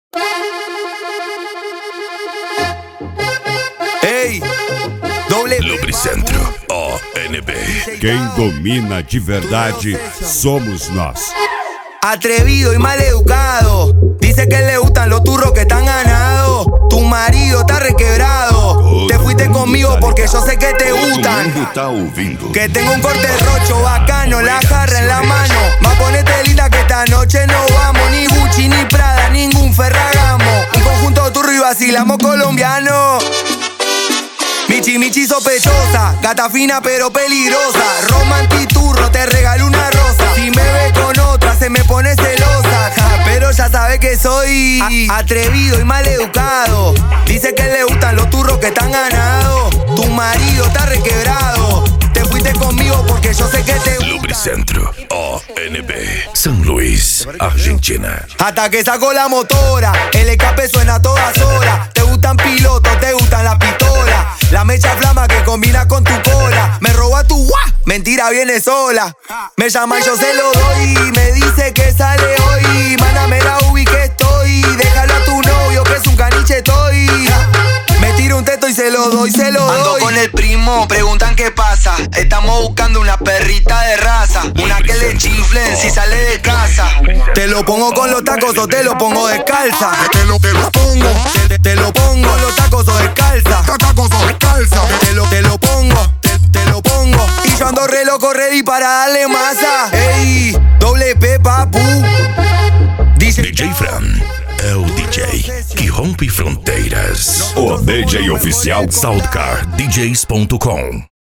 Cumbia
Funk
Remix